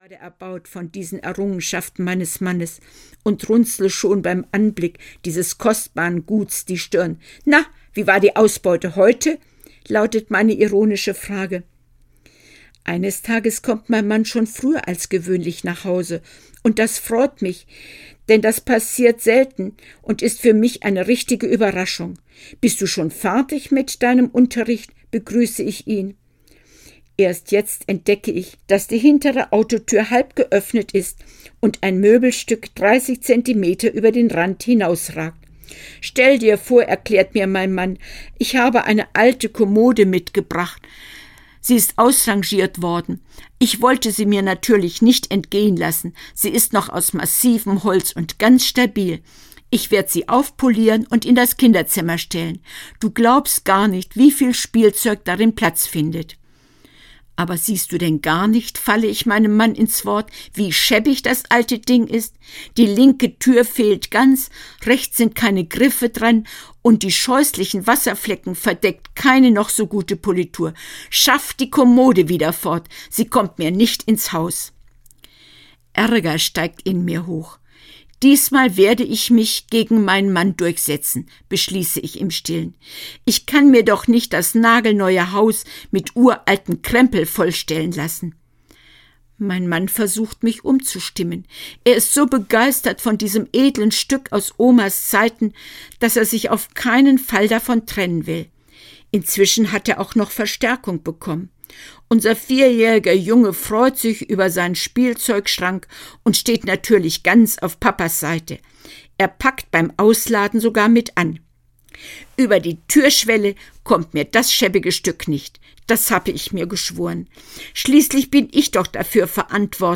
Ein Blumenstrauß voll Leben - Lotte Bormuth - Hörbuch